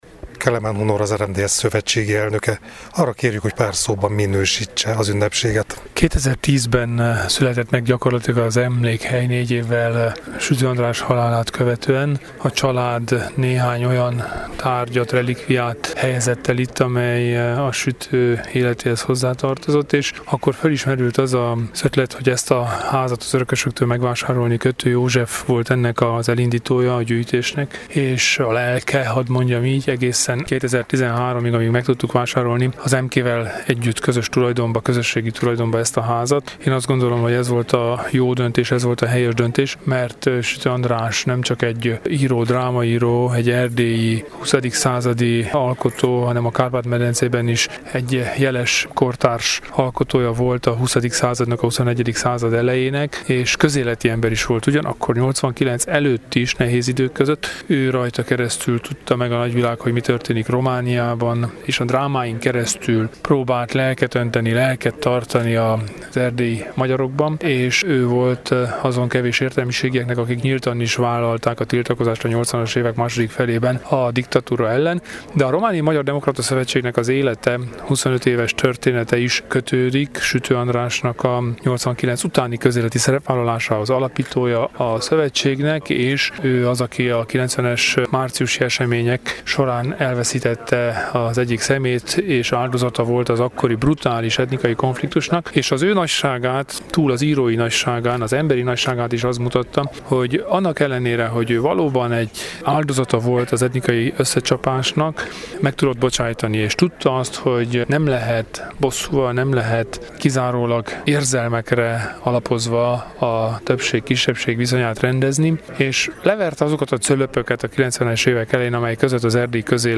Vasárnap, ünnepi külsőségek közepette adták át rendeltetésének a mezőségi Pusztakamaráson a Sütő András emlékházat.